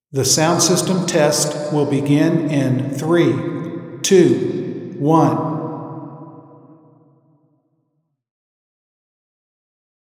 ↑ Auralization of predicted RIR.